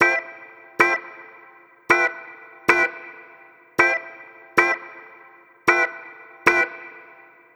Swingerz 3 Organ-D.wav